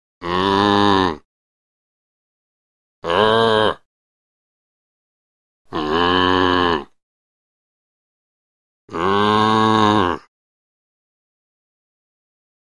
Cow Moos - 4 Effects; Cow Moos